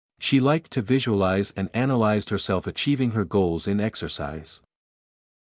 ※当メディアは、別途記載のない限りアメリカ英語の発音を基本としています。
中上級/z/の発音